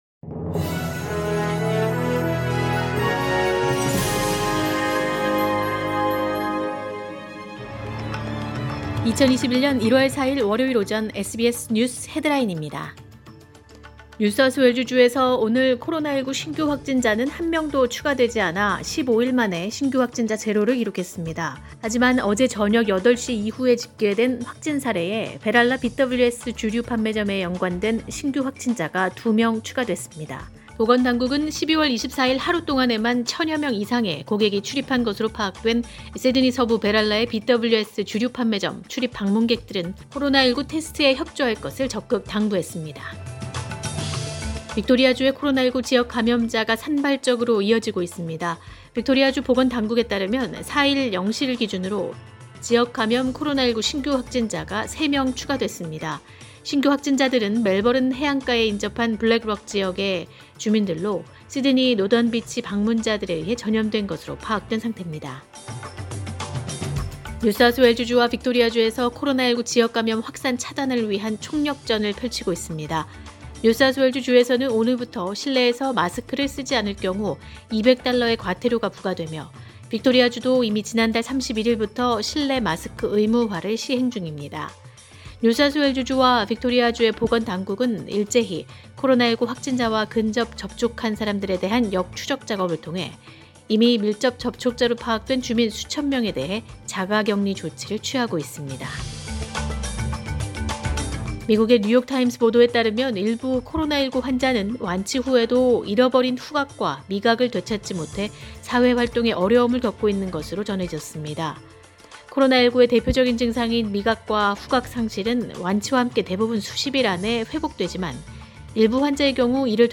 2021년 1월 4일 월요일 오전의 SBS 뉴스 헤드라인입니다.